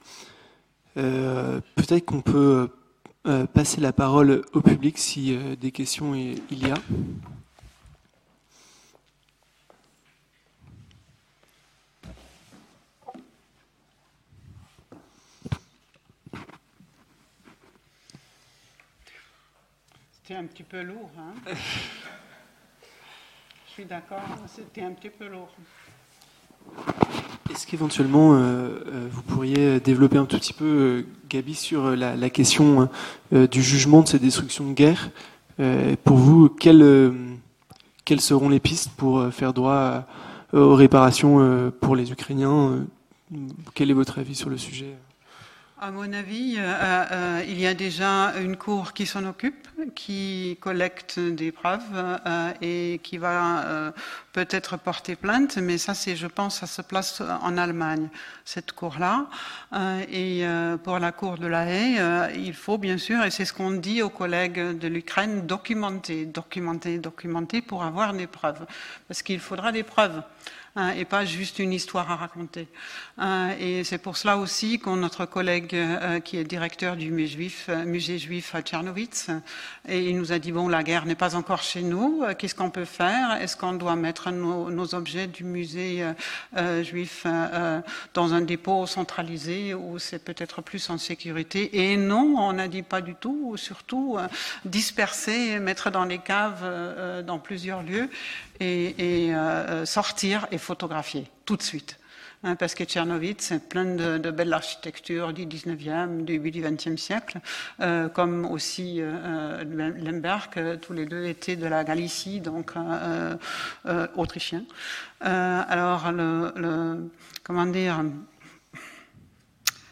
Discussion - Les droits culturels les communs favorisent-ils l'accès à la culture ?